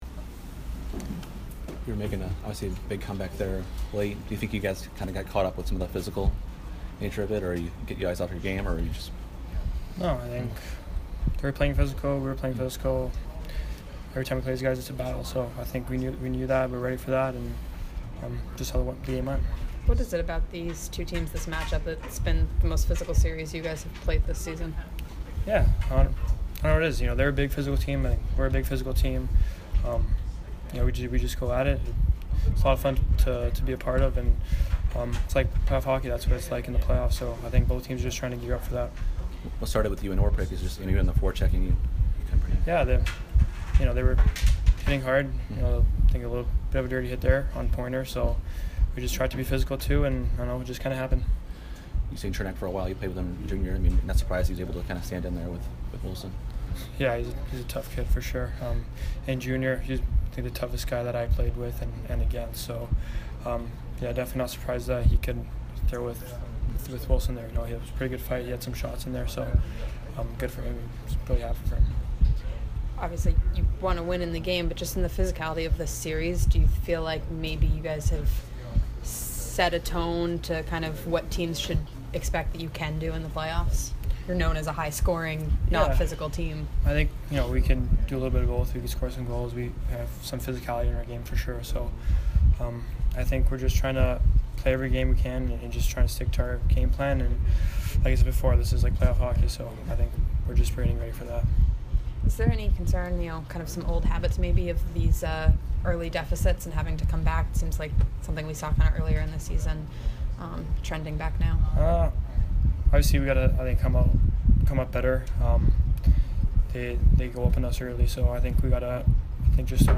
Anthony Cirelli post-game 3/30